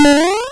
BigJump.wav